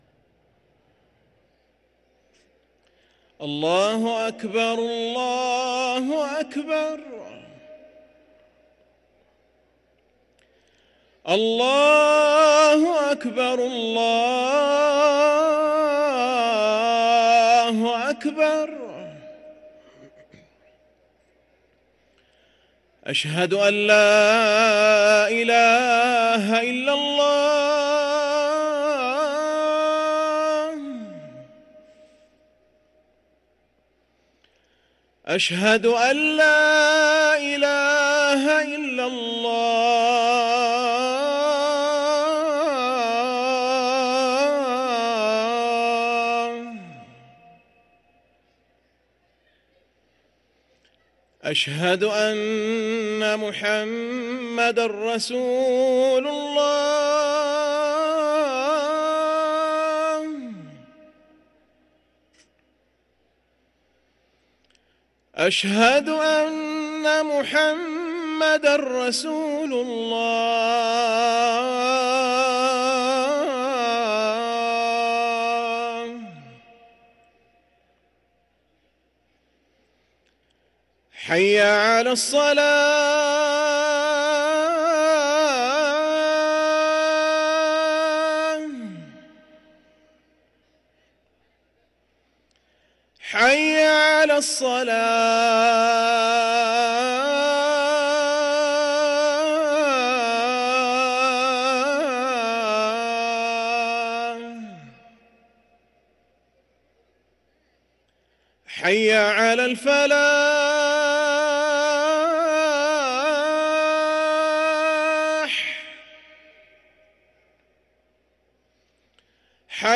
ركن الأذان 🕋